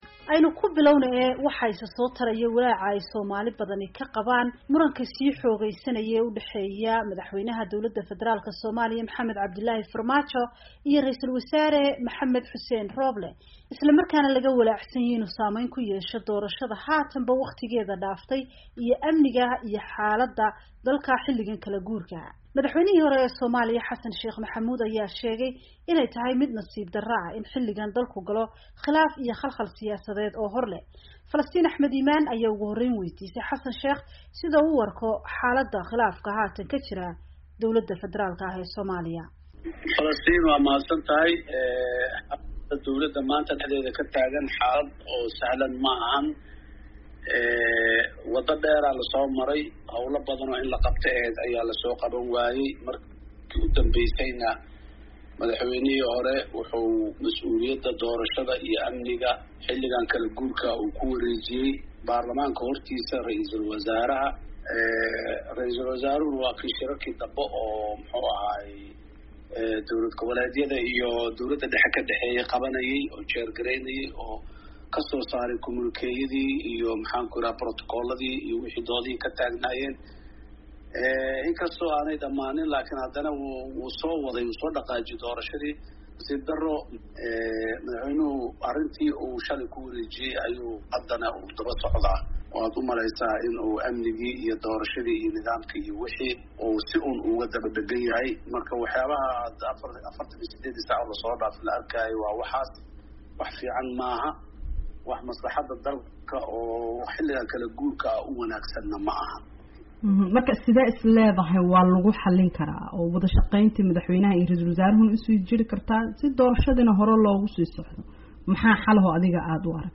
Wareysi: Xasan Sheekh oo ka hadlay khilaafka Farmaajo iyo Rooble